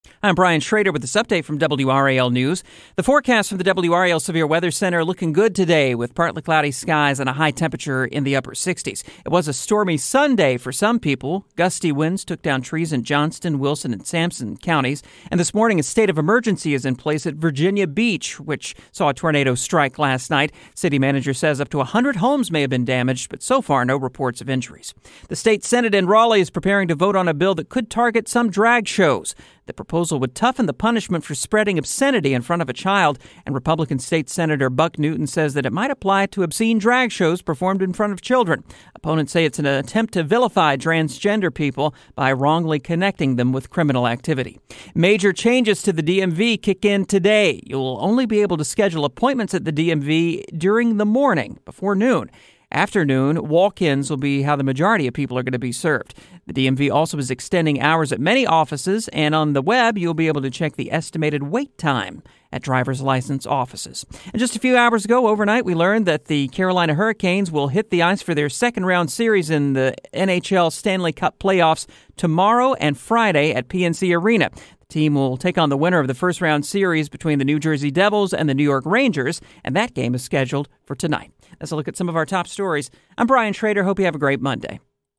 WRAL Newscasts